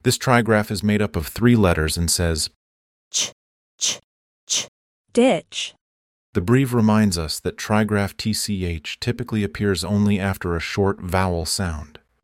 This trigraph is made up of three letters and says: /ch/, /ch/, /ch/, ditch.